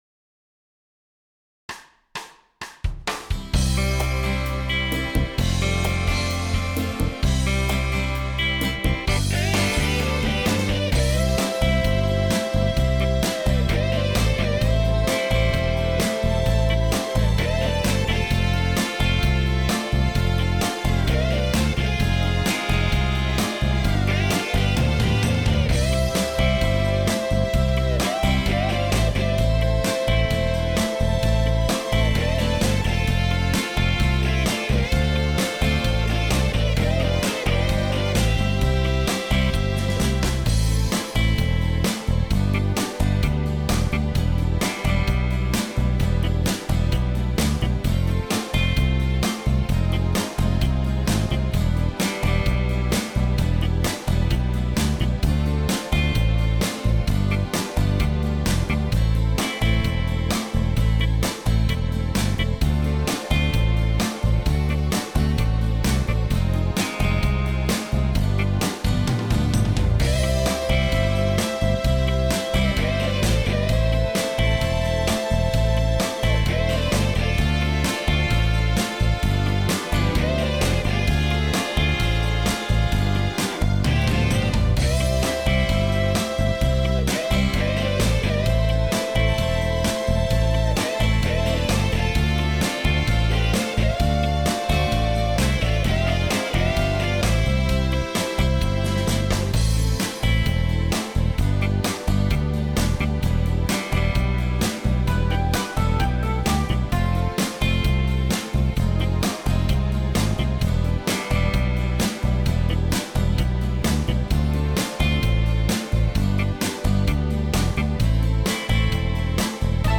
минусовка версия 51630